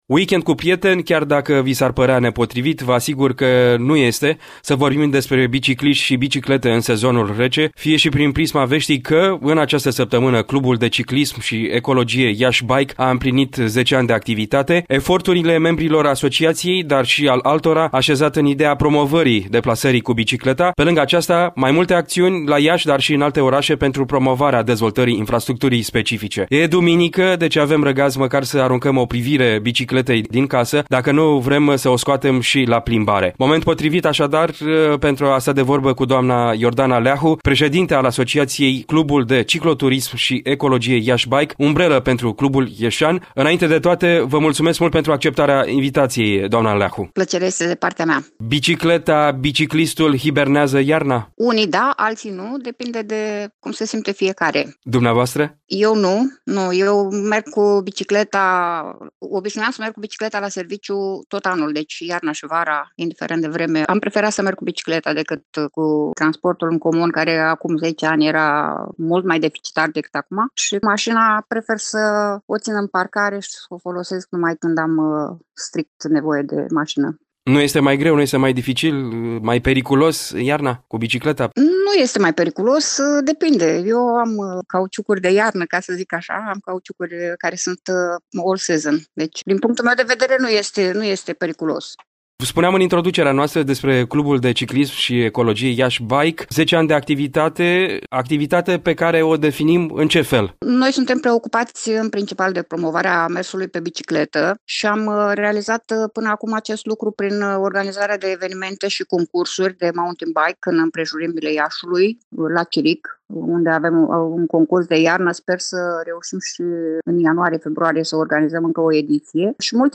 Un interviu